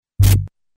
Efectos